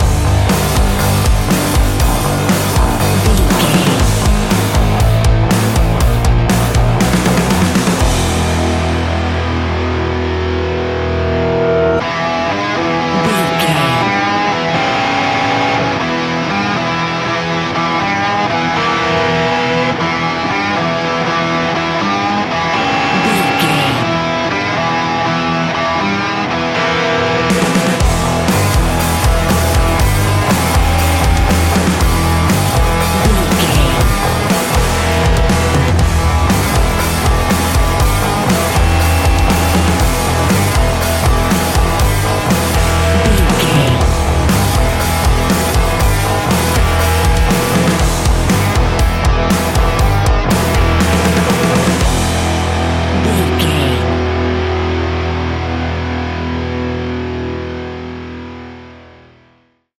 Ionian/Major
E♭
hard rock
guitars
heavy metal
instrumentals